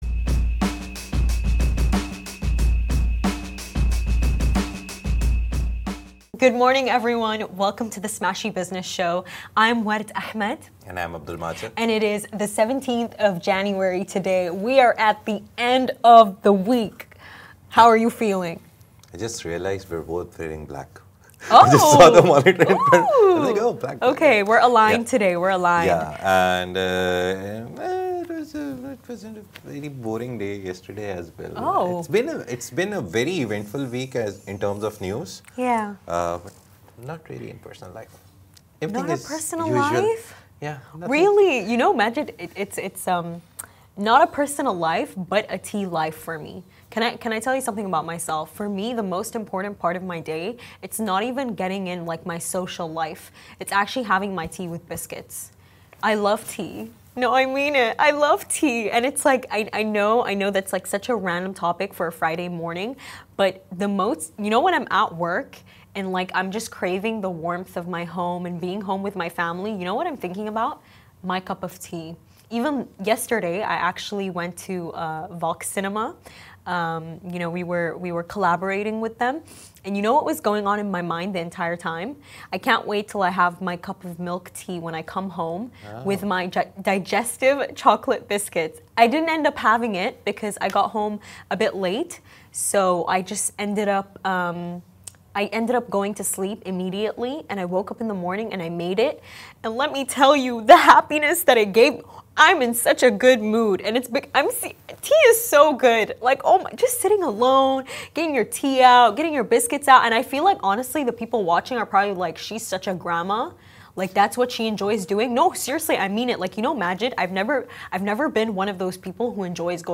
The Smashi Business Show is where Smashi interviews the business leaders who make a difference in this great city.